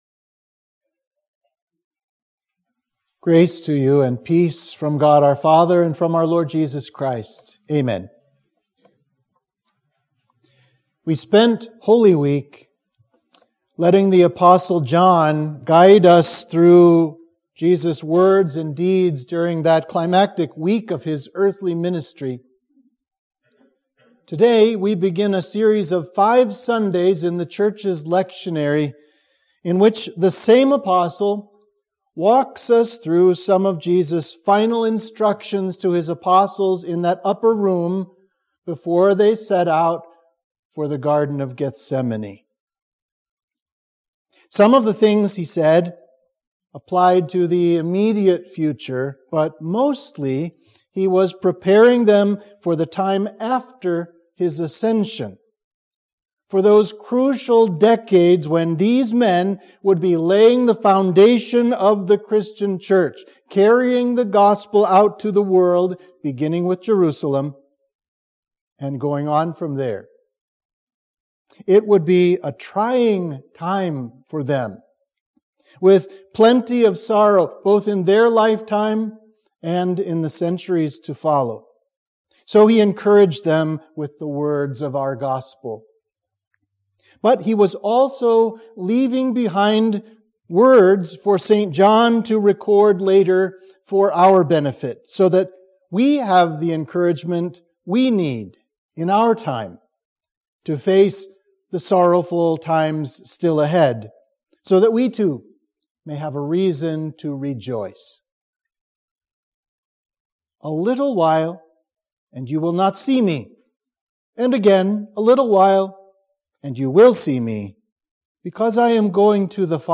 Sermon for Easter 3 – Jubilate